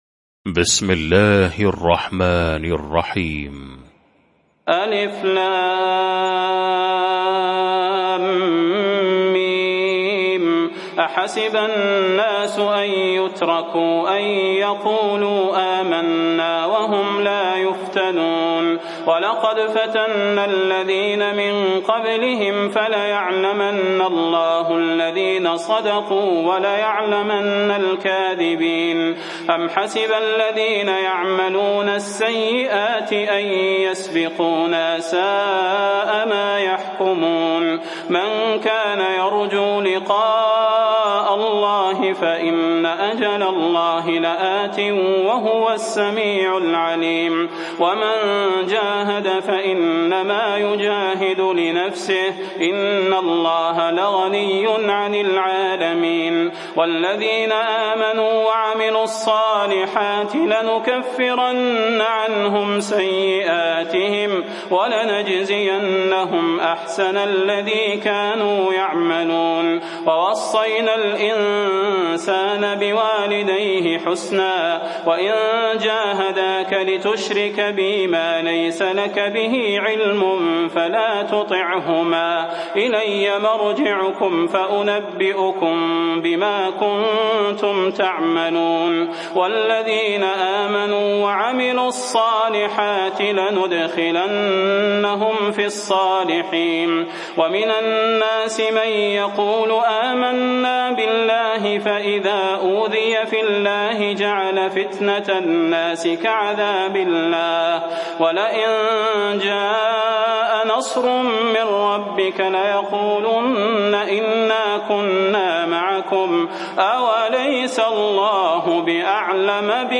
المكان: المسجد النبوي الشيخ: فضيلة الشيخ د. صلاح بن محمد البدير فضيلة الشيخ د. صلاح بن محمد البدير العنكبوت